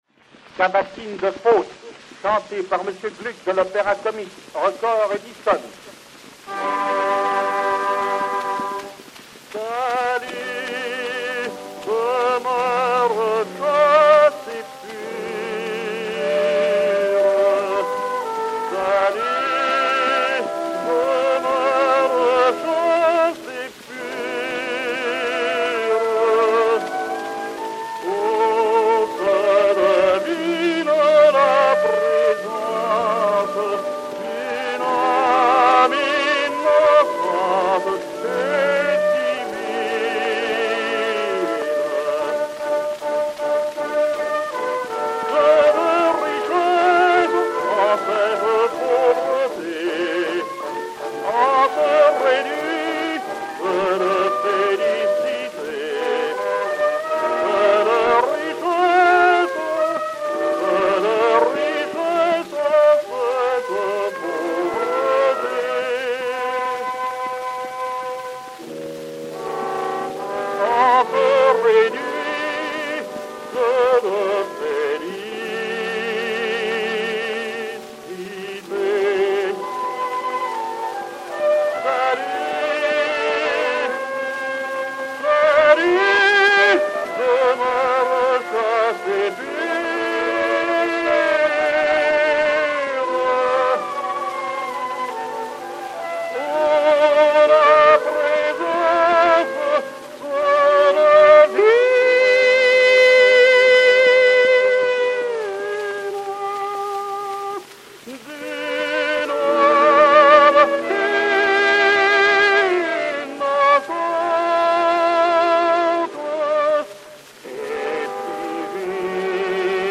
ténor de l'Opéra-Comique Orchestre
cylindre Edison 17336, enr. à Paris en 1905/1906